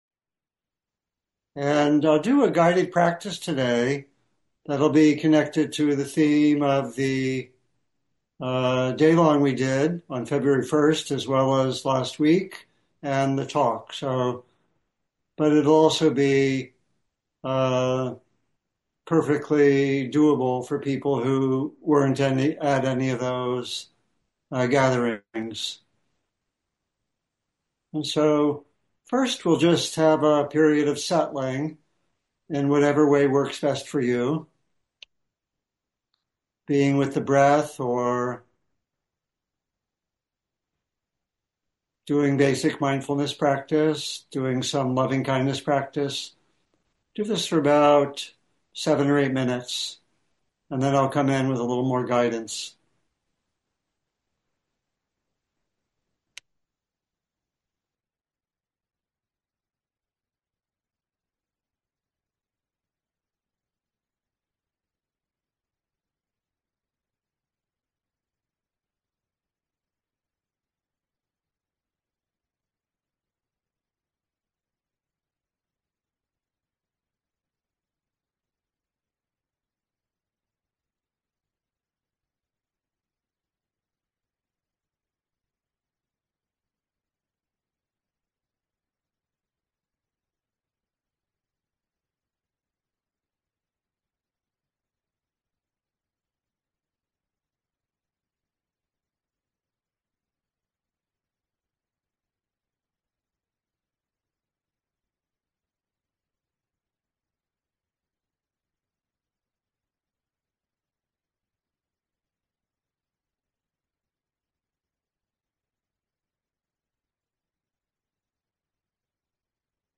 Guided Meditation Exploring the Constructions of Experience